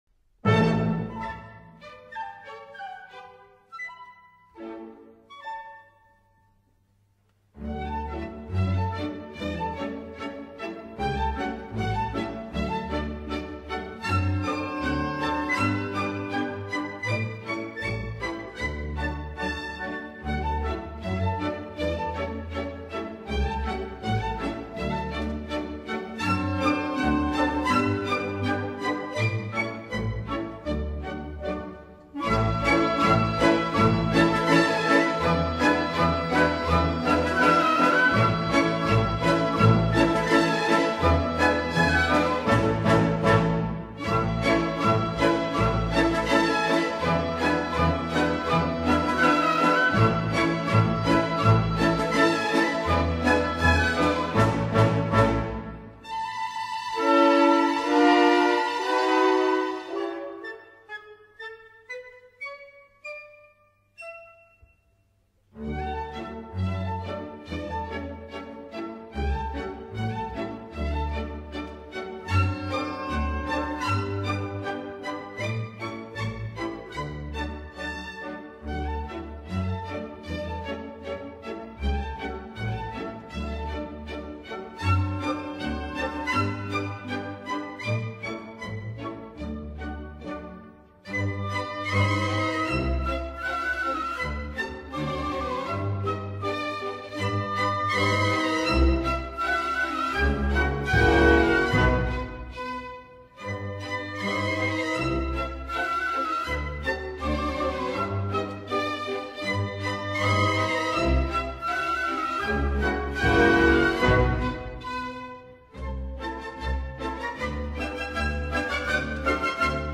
Annen Polka op. 117 - Johann Strauss_(junior)